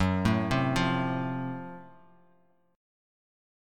F#mM7b5 chord